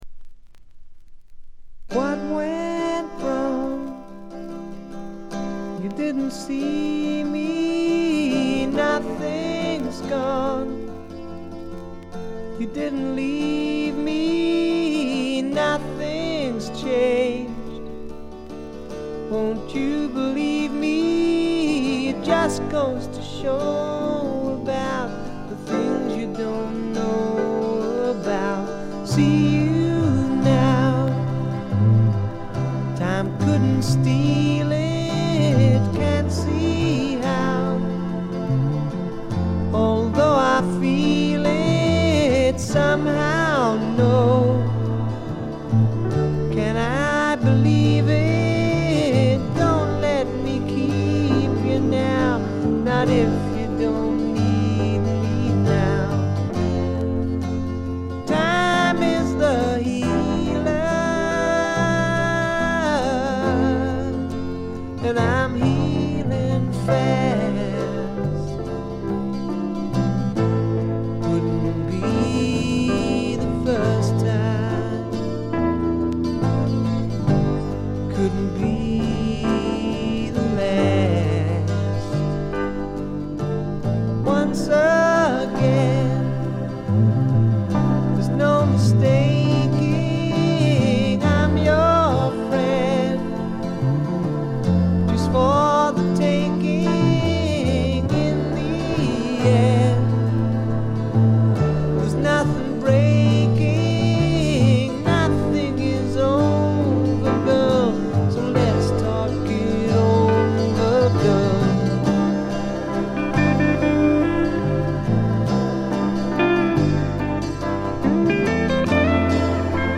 見た目に反してところどころでチリプチが出ますが大きなノイズはありません。
それに加えて激渋ポップ感覚の漂うフォークロック作品です。
この人の引きずるように伸びのあるヴォーカルは素晴らしいです。
試聴曲は現品からの取り込み音源です。